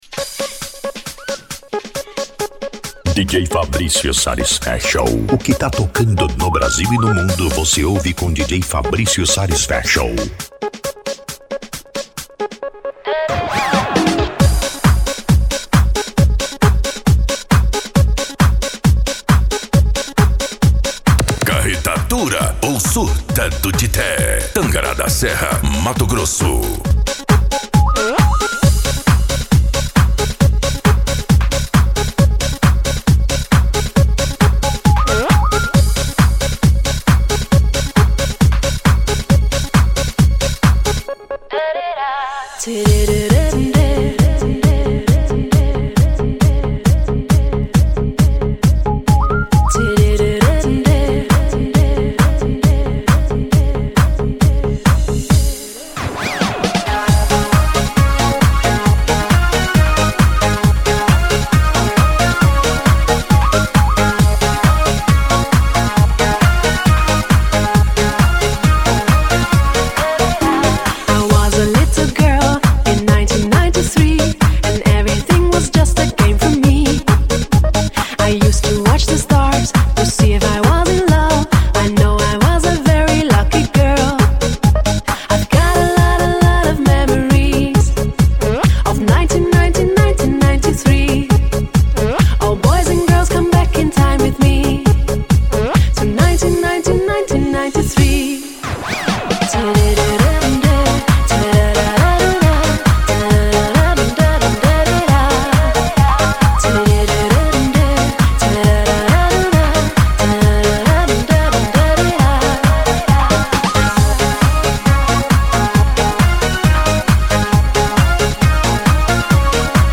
Euro Dance
Funk
Sets Mixados